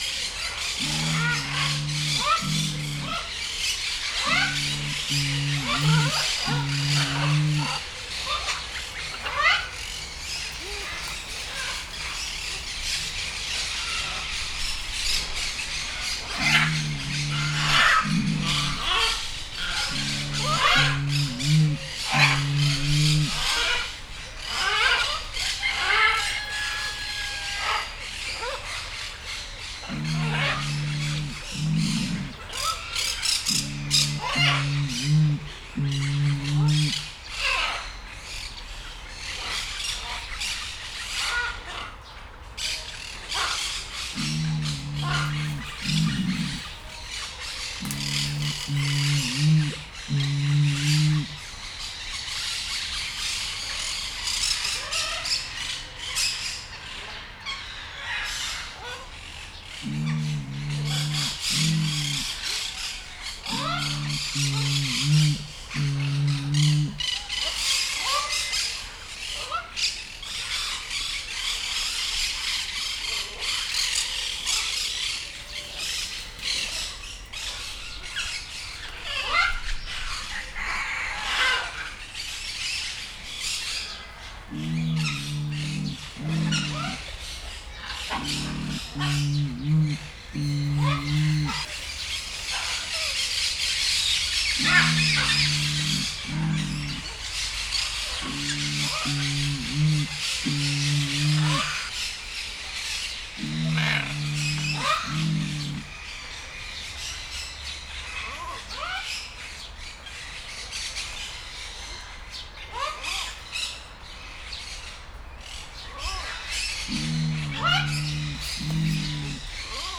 Directory Listing of //allathangok/nyiregyhazizoo2019_professzionalis/csupaszarcu_hokko/
egyenleteshang_nyiregyhaza0206.WAV